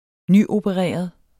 Udtale [ ˈny- ]